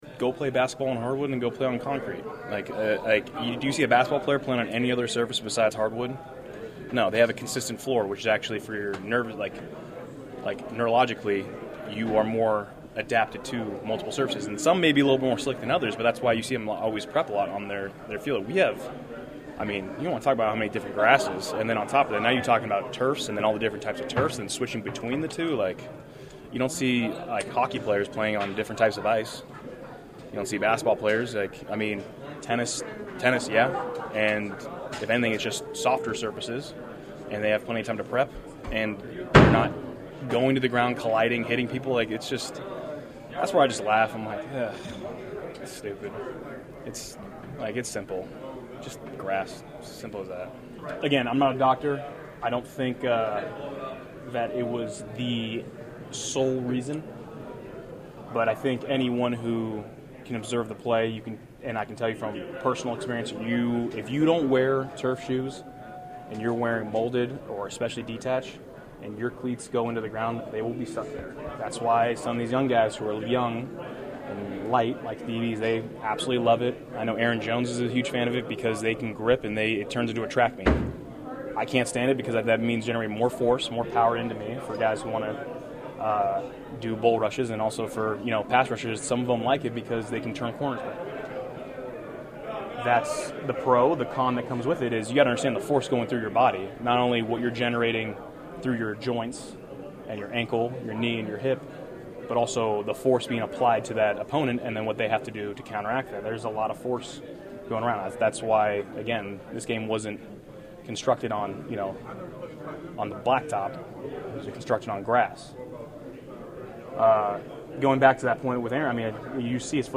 Bakhtiari also spoke at length after another practice he didn’t participate in about the raging debate about turf versus grass at NFL venues.